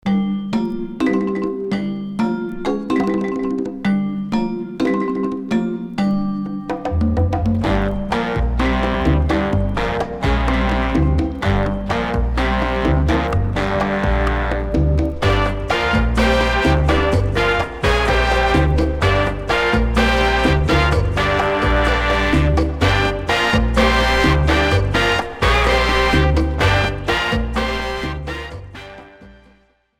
• Качество: 192, Stereo
без слов
Cover
инструментальные
латина
оркестр
электроника
ча-ча-ча
cha-cha-chá